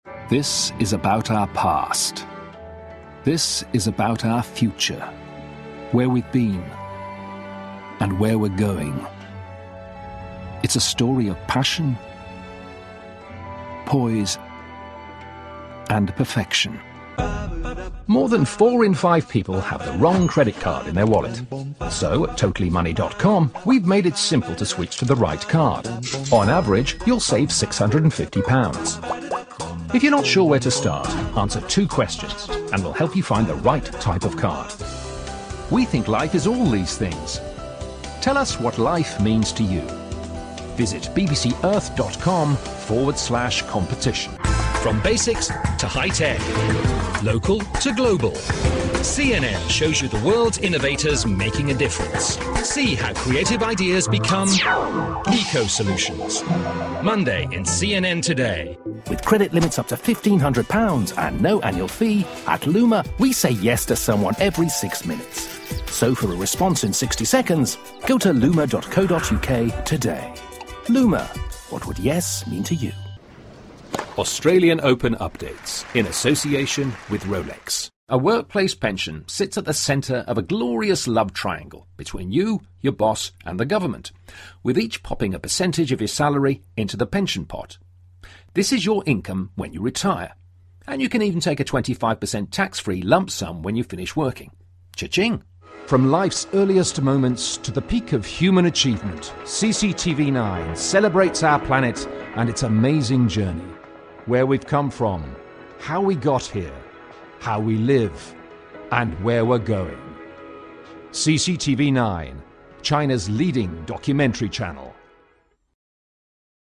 Male 30s , 40s , 50s , 60s , 60s +
Approachable , Assured , Authoritative , Character , Confident , Corporate , Deep , Energetic , Engaging , Friendly , Gravitas , Reassuring , Versatile , Warm